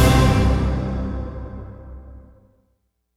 Hit (12).wav